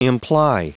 Prononciation du mot imply en anglais (fichier audio)
Prononciation du mot : imply